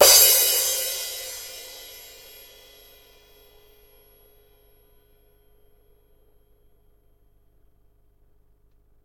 混合镲片 " Crash1
我相信话筒是AKG 414。在录音室环境中录制的。